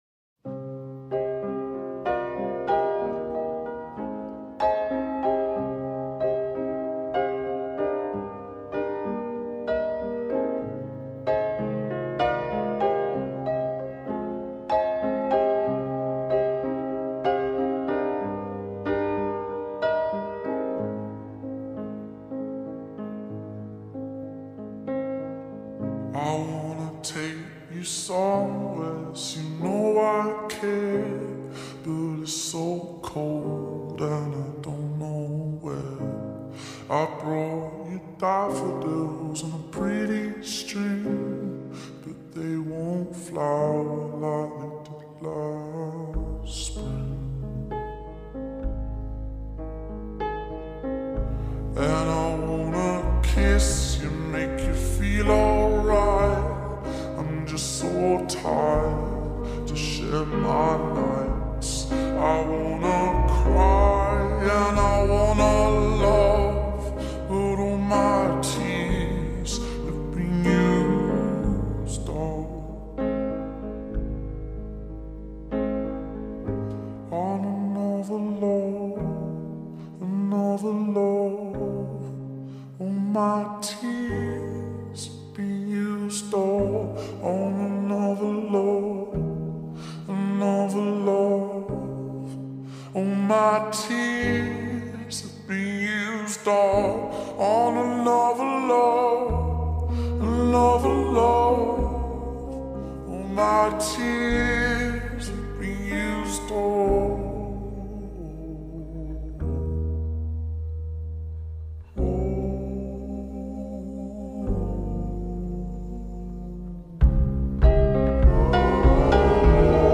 با ریتمی کند شده
غمگین
عاشقانه